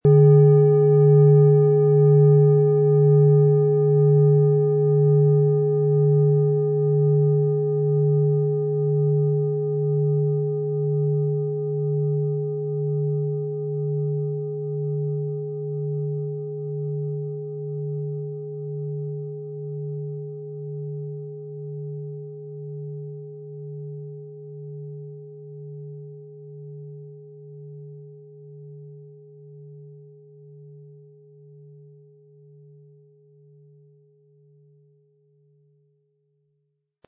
• Mittlerer Ton: Uranus
• Höchster Ton: Tageston
PlanetentöneSaturn & Uranus & Tageston (Höchster Ton)
MaterialBronze